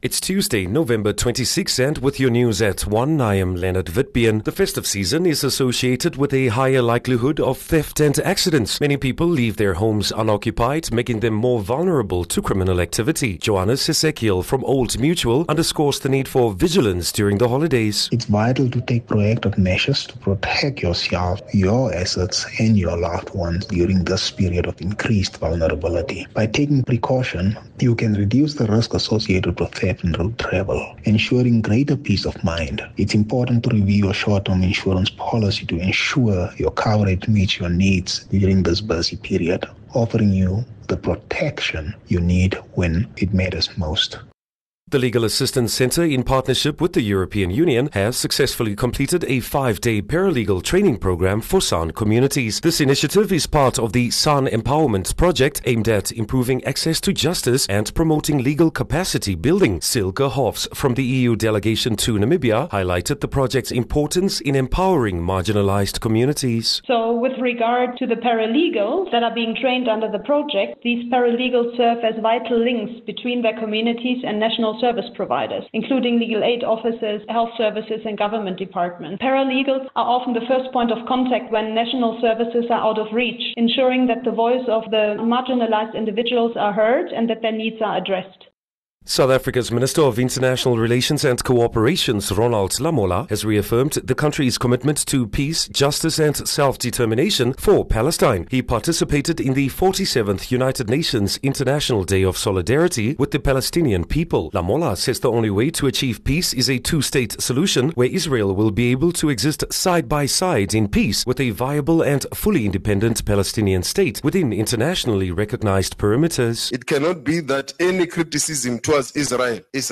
Daily bulletins from Namibia's award winning news team.